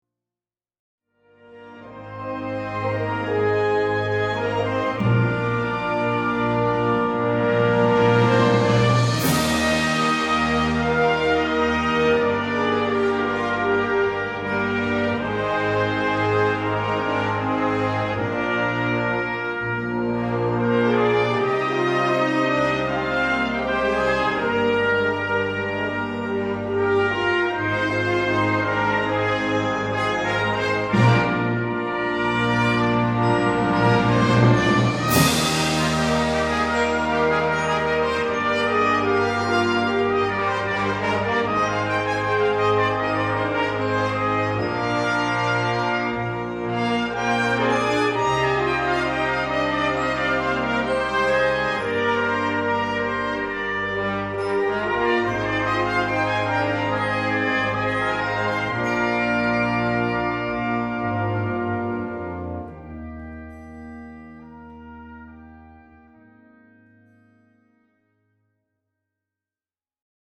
Gattung: Choral für Blasorchester
Besetzung: Blasorchester